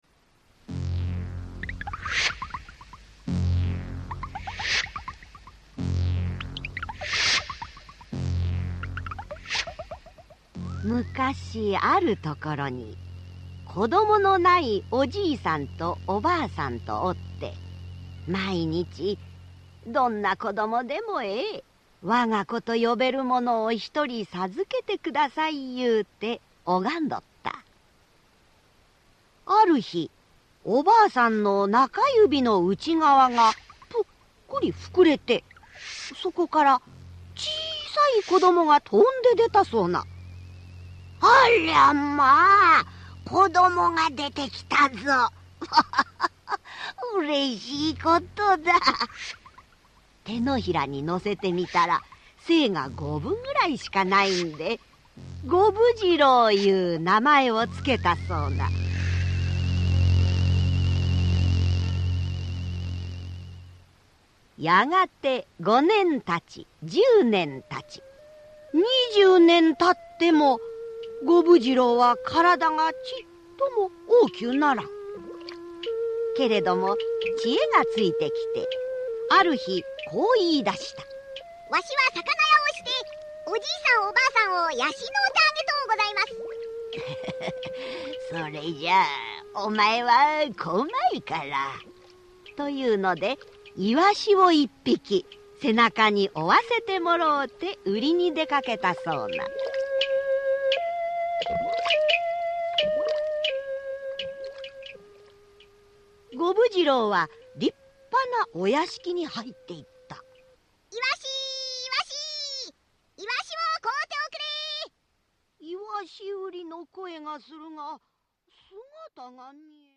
[オーディオブック] 五分次郎